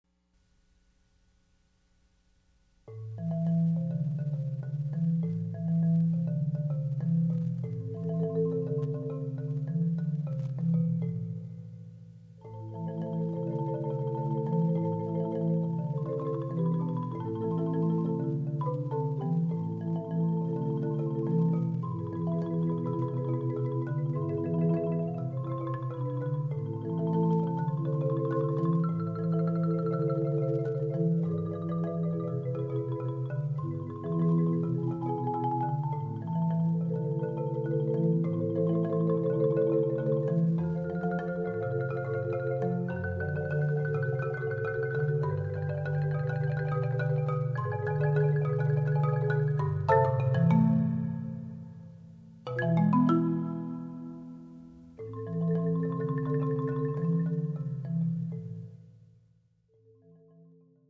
for Marimba Trio